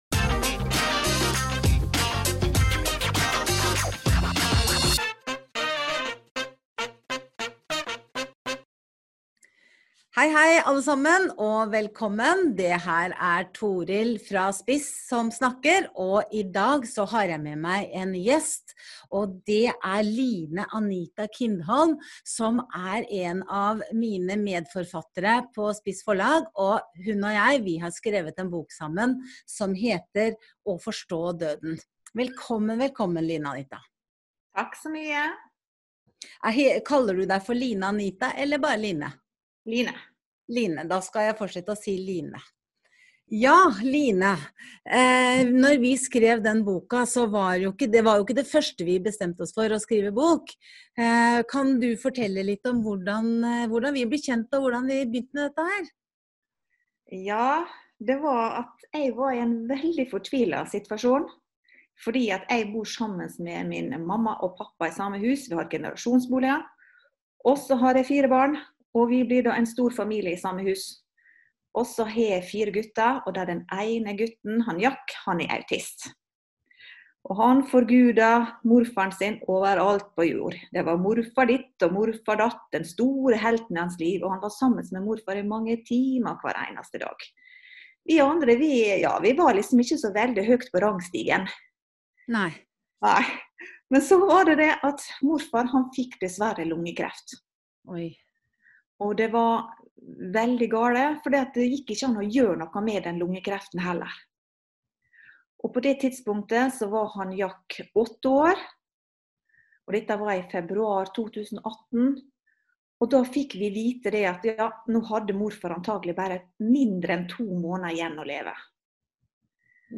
Samtale